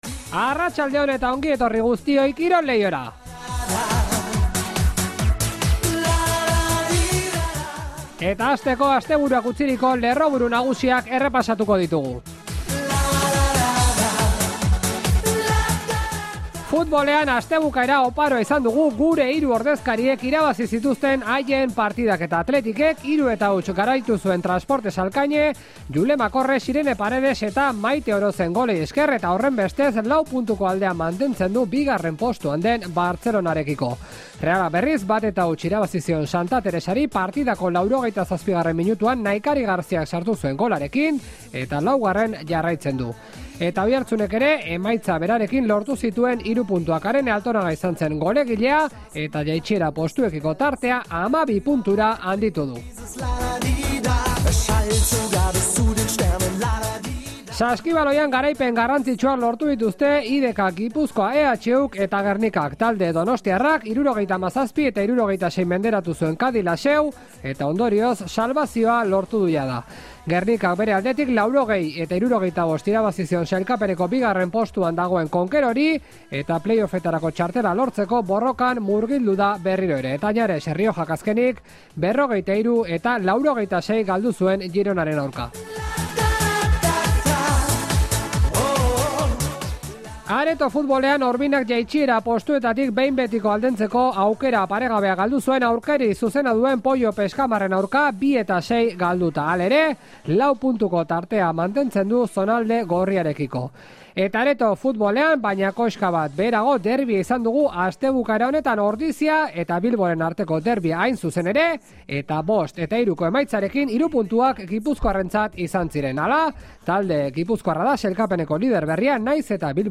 Gaurko saioan Maialen Chourraut elkarrizketatu dugu. Lasartearrak asteburu honetan lortu du Olinpiar Jokoetarako txartela eta oso gustura dago.